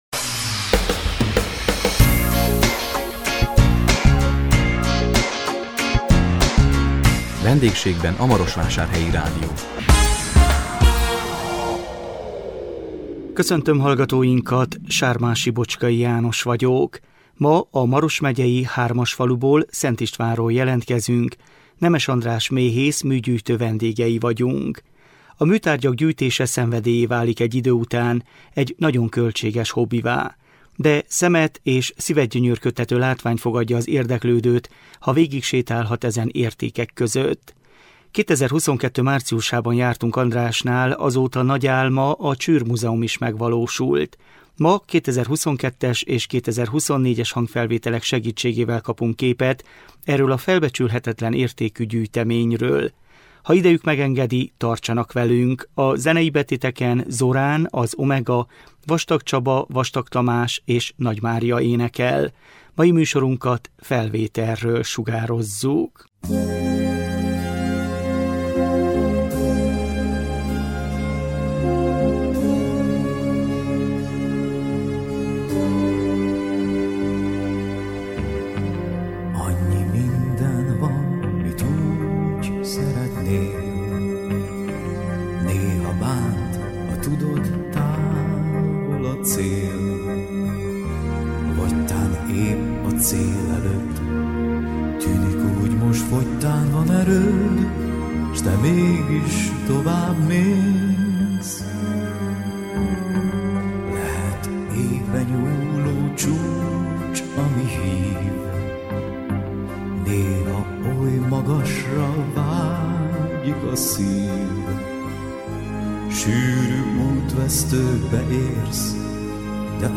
Ma 2022-es és 2024-es hangfelvételek segítségével kaptunk képet erről a felbecsülhetetlen értékű gyűjteményről.